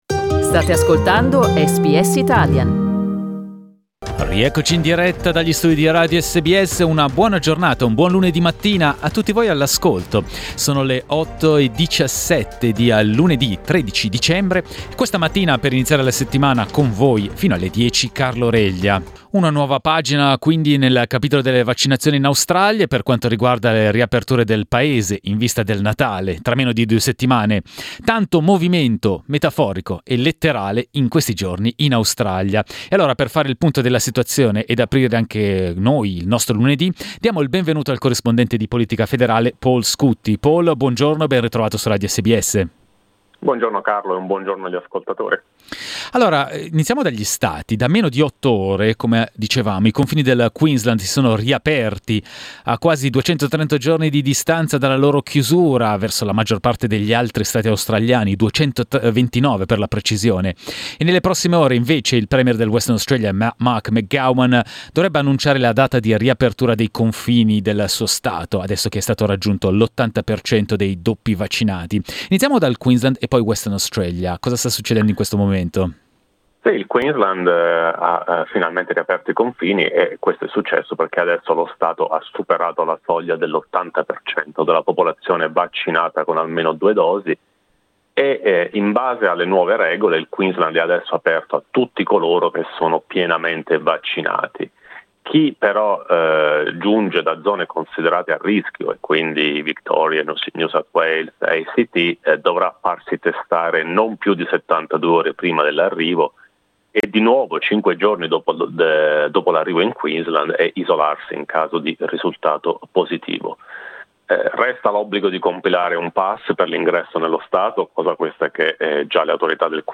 Ieri nel NSW è stato registrato il primo caso di persona affetta da variante Omicron che ha dovuto essere ricoverata in ospedale. Ascolta il servizio del corrispondente di politica federale